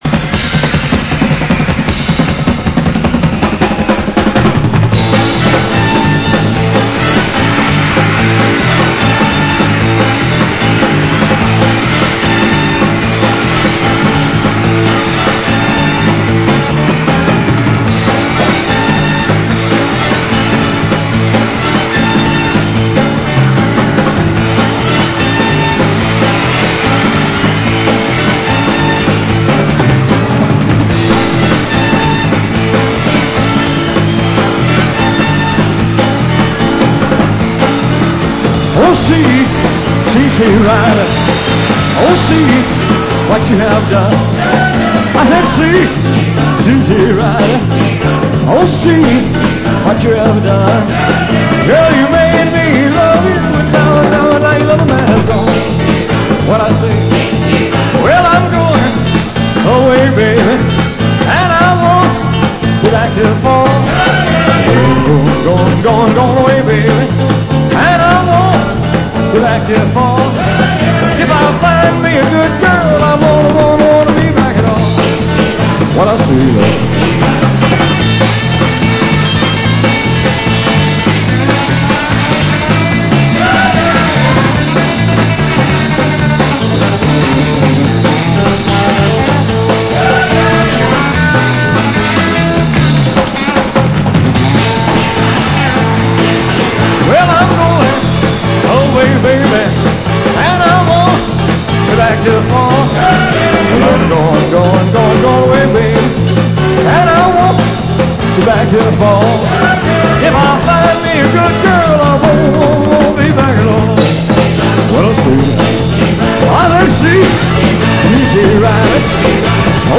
Keine CD Qualität, von Schallplatte!!!!!!